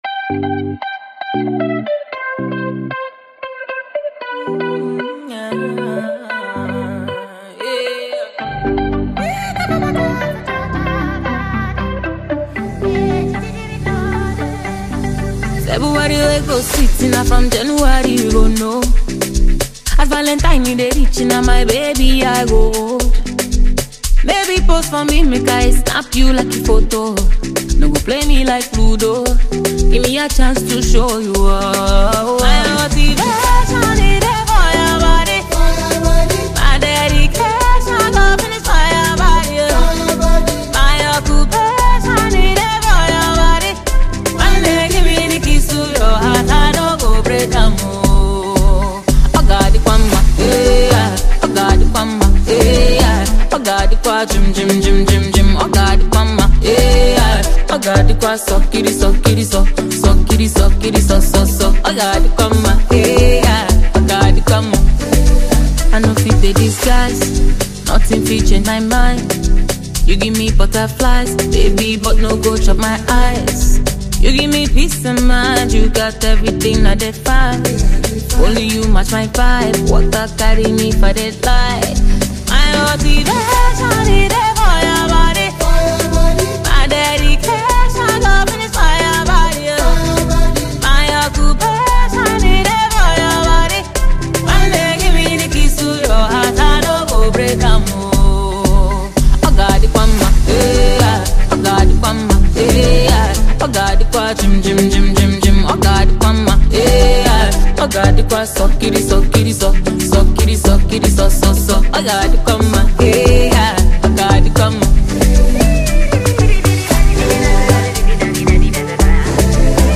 Renowned talented Female music performer and crooner